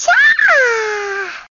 Звуки пикачу
Пикачу радостно удивлен